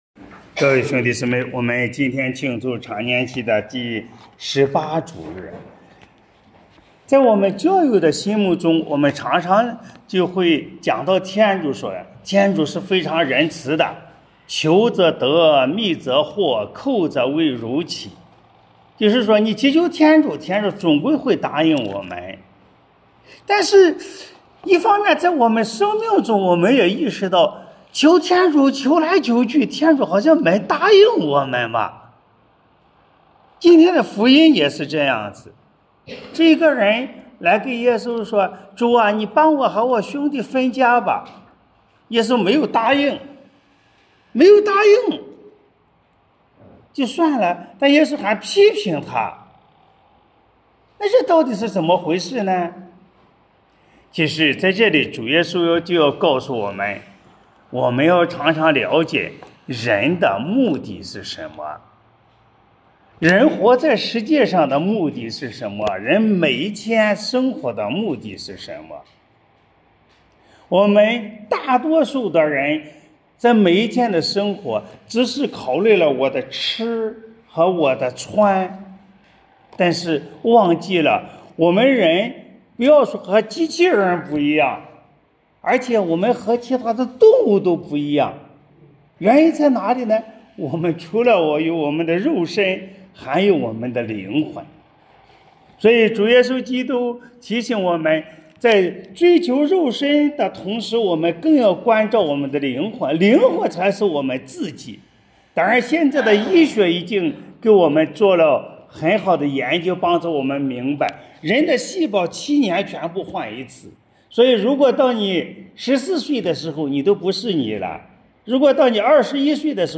【主日证道】| 财富是福还是祸？